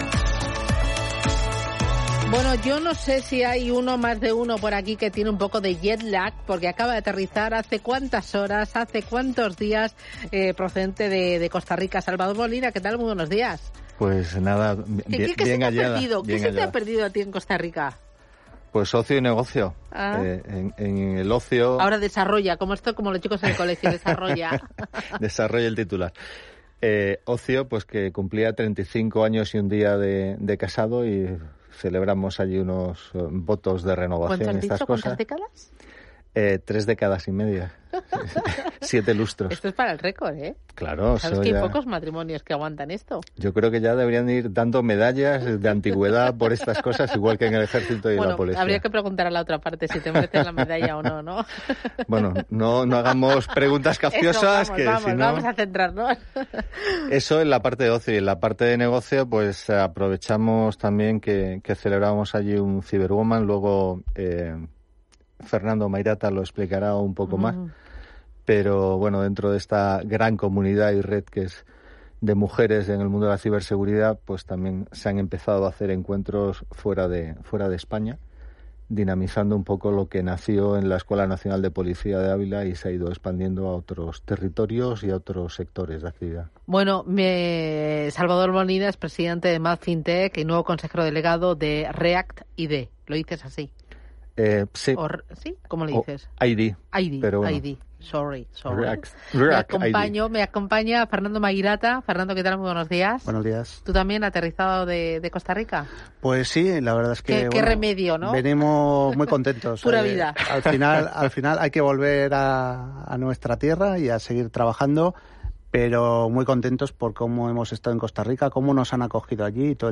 La comunidad C1b3rwoman, una red que empodera a mujeres para que lideren el sector de la ciberseguridad, reunió en Radio Intereconomía a algunos de sus impulsores para contar cómo fue su primera edición internacional, que eligió Costa Rica para abrir una nueva etapa. Y también celebraron la reciente concesión del premio de Startup Olé a “The Best Iniciative 2025”.